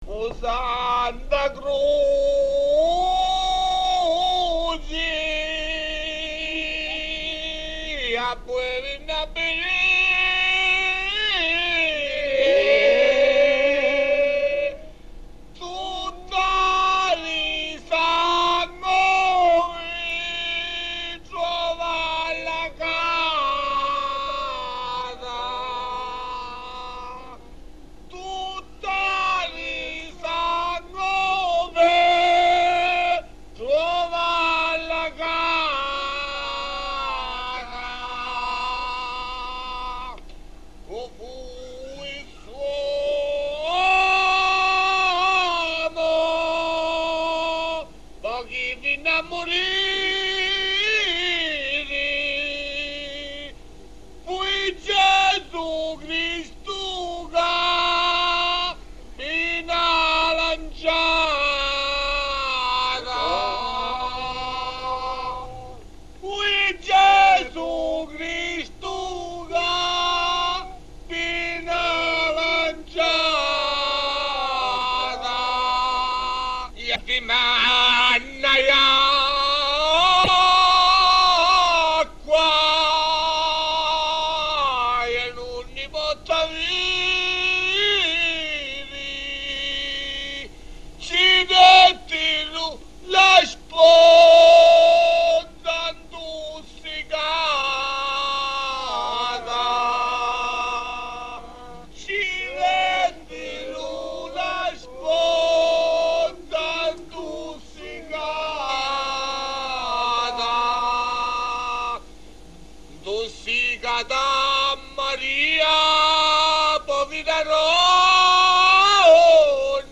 I documenti presenti in questo archivio sono in formato mp3 e sono stati digitalizzati e restaurati recentemente da supporti audio tradizionali. La qualità di alcuni, comunque, non è ottimale.
O Santu Cruci, brano del Venerdì Santo